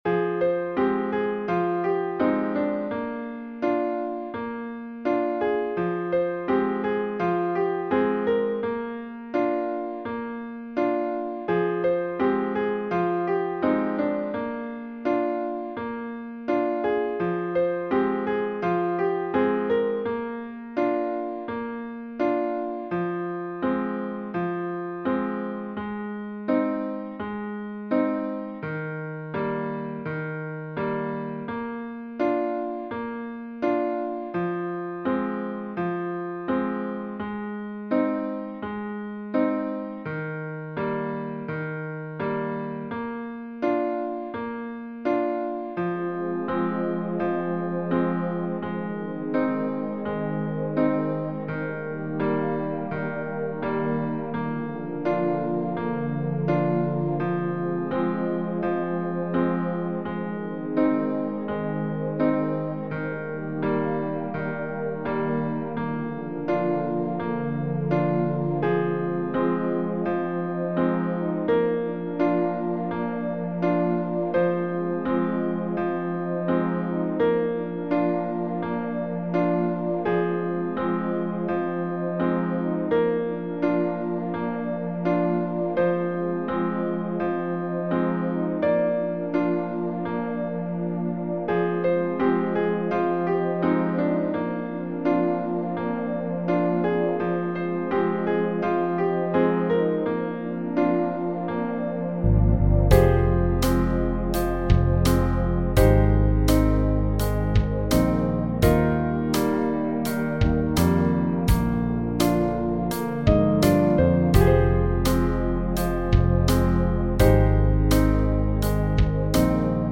Runterladen (Mit rechter Maustaste anklicken, Menübefehl auswählen)   Mad World (Playback)
Mad_World__5_Playback.mp3